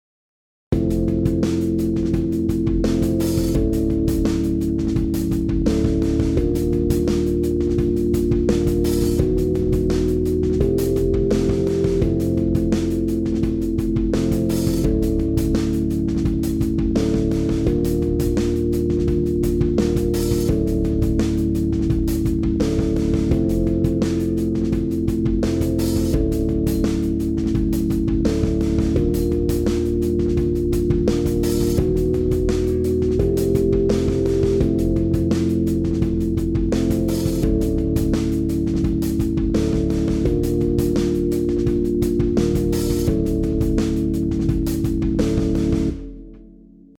משהו חדש שניסיתי יש אחלה משחק בסאוונד של התופים (כמו בפעם שעברה אני יותר יוצר לופים של מנגינות אקראיות אבל בלי סולו אולי אחרי זה…)
אני יודע שהתופים קצת בוציים אבל אני חושב שזה חלק מהקטע של זה כאילו אתה בתוך מים נראה לי שאולי אחר כך אני יעמעם את זה יותר…